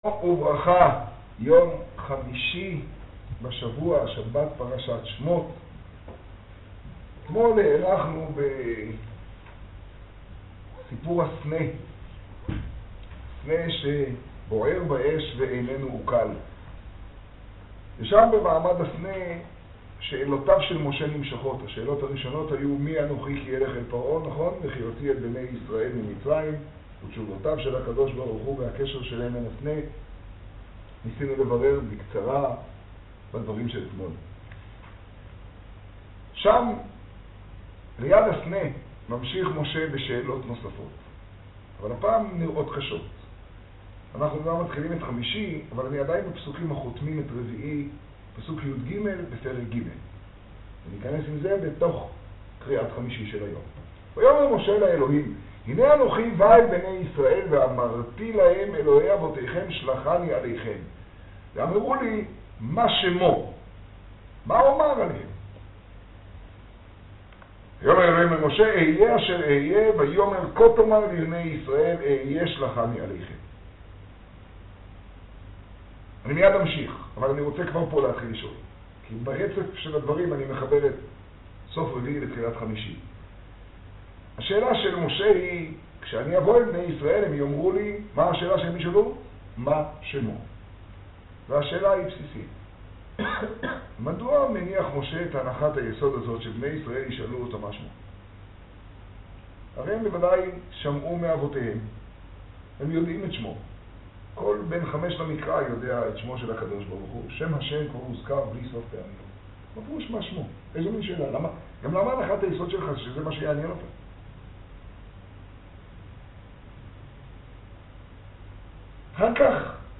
האזנה קטגוריה: מגדל - היום בפרשה , שיעור , תוכן תג: בראשית , ויחי , חומש , תשעה → רמבם יומי – יז טבת תשעה מעמד הסנה ←